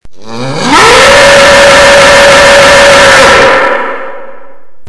Когда создаешь команду, то можно выбрать сирену быка.
всё банально просто - запись через звуковой редактор и редактирование в нём же wink